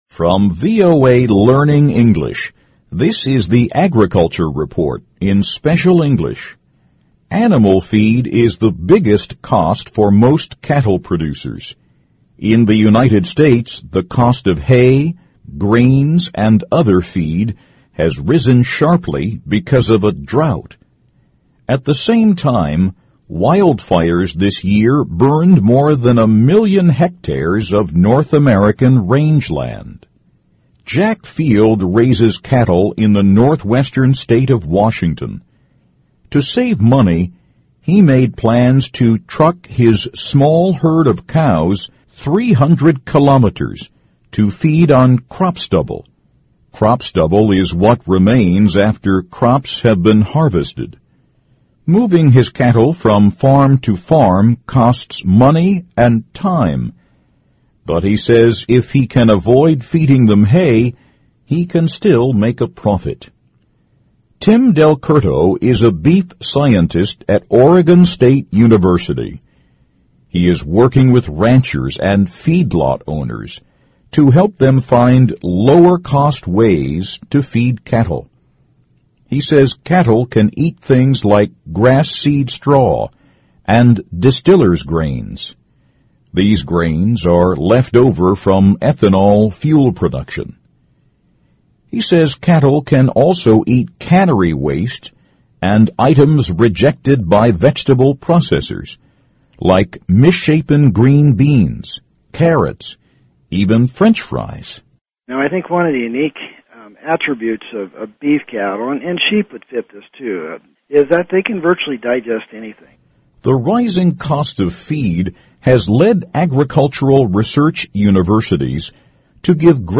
VOA慢速英语 Agriculture Report - Improving 'Feed Efficiency' in Cattle 听力文件下载—在线英语听力室